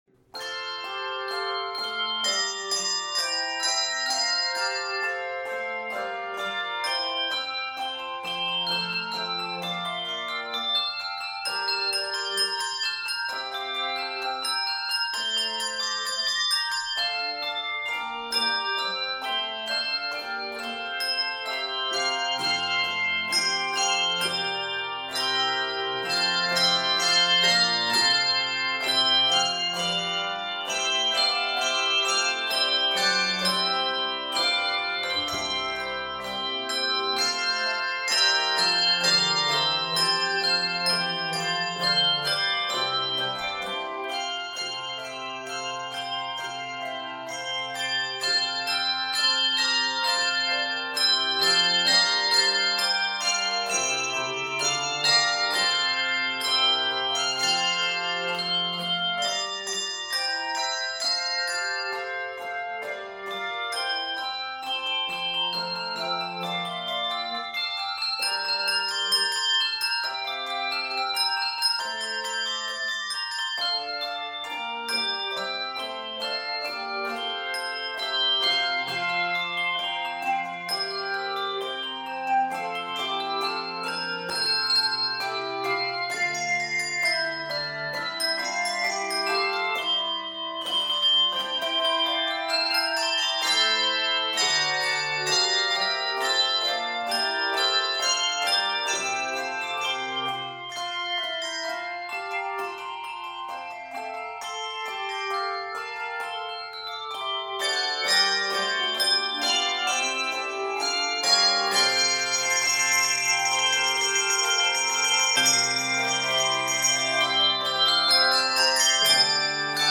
Keys of F Major and G Major.
Octaves: 3-7